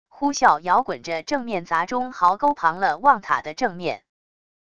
呼啸摇滚着正面砸中壕沟旁了望塔的正面wav下载